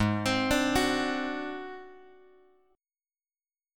G# 7th Flat 5th